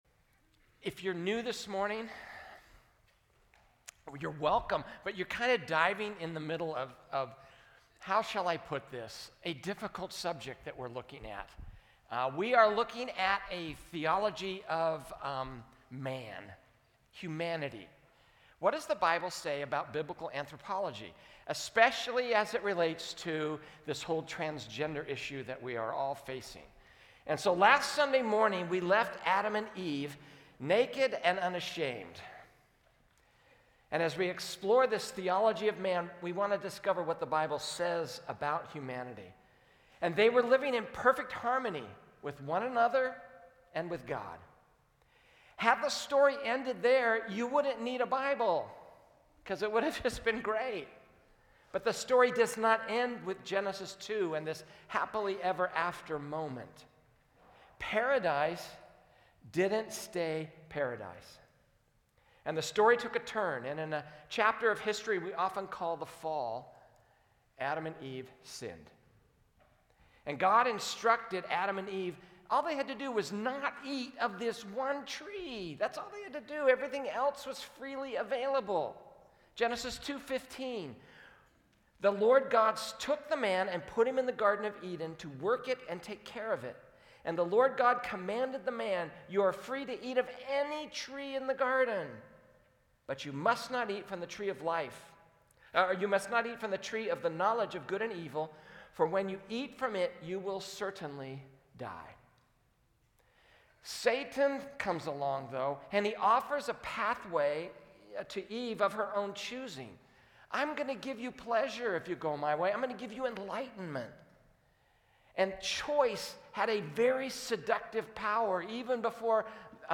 A message from the series "IMAGO DEI."